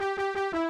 missed.wav